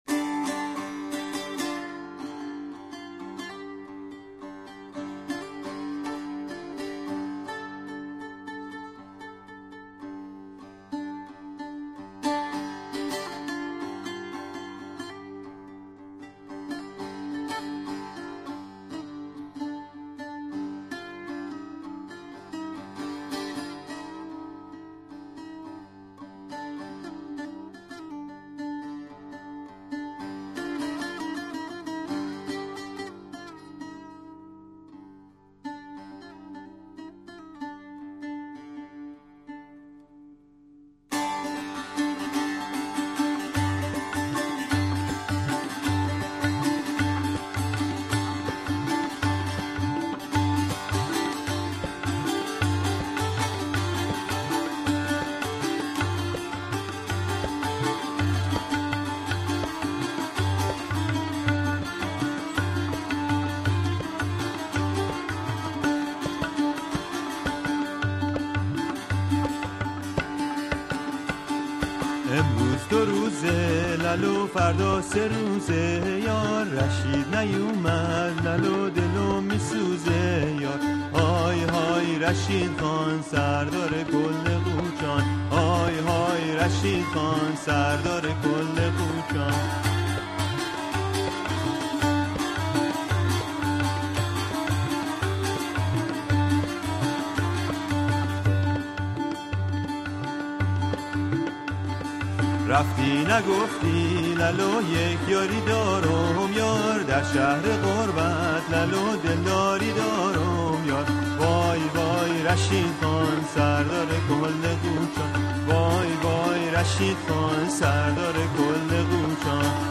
Persian Folk Songs
sung with accompaniment